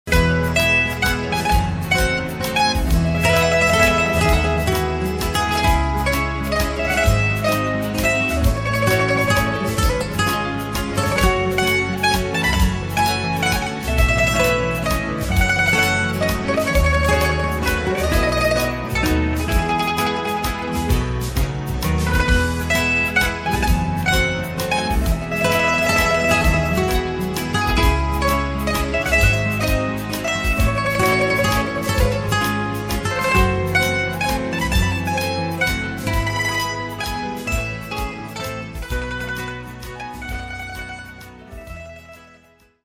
mandolino
chitarra